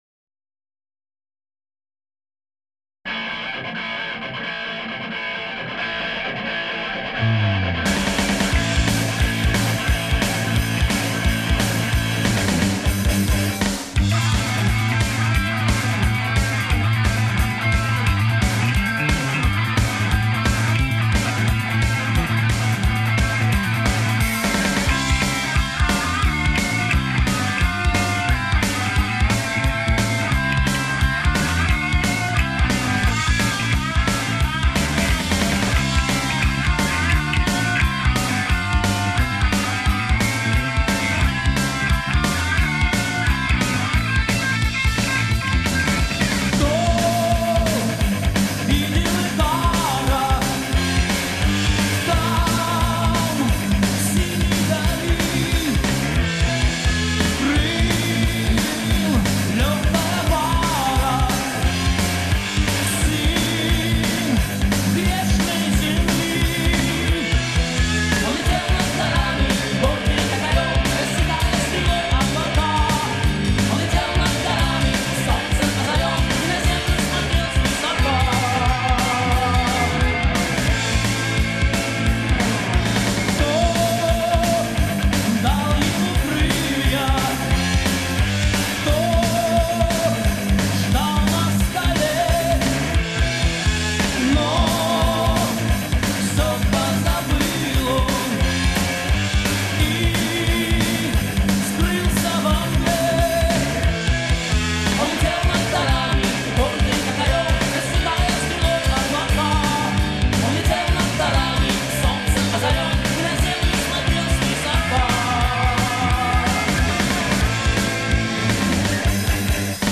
русский рок